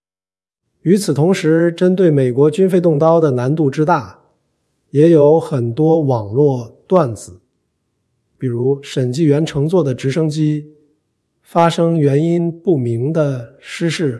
f5tts - F5-TTS wrap module